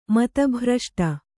♪ mata bhraṣṭa